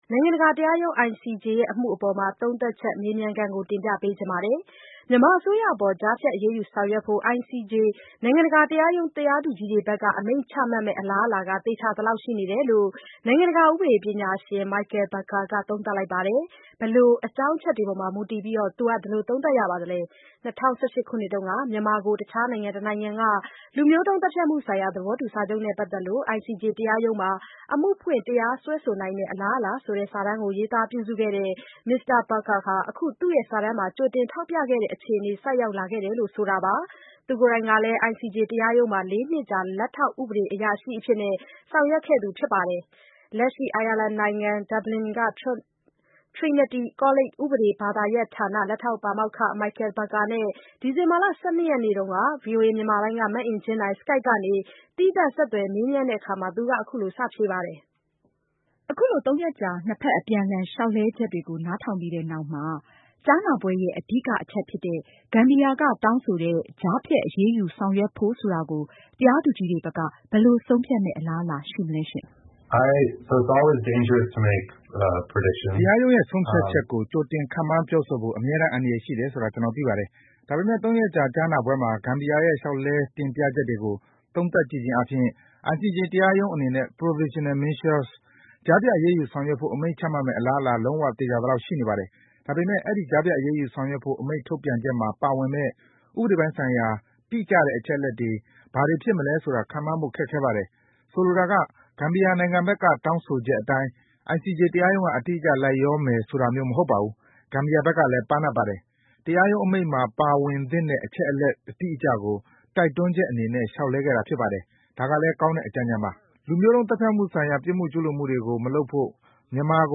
Skype ကနေ သီးသန့် ဆက်သွယ်မေးမြန်းထားတာပါ။